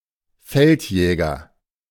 The Feldjäger (German: [ˈfɛltˌjɛːɡɐ]